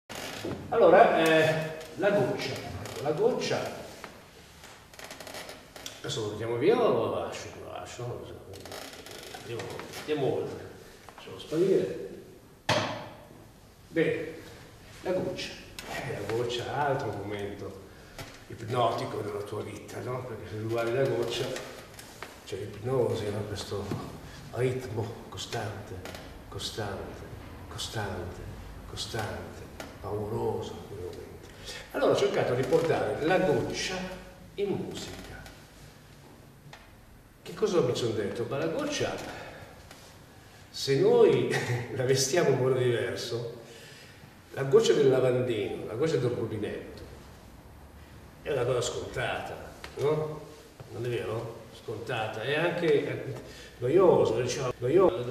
IV OTTOBRE MUSICALE A PALAZZO VALPERGA
pianista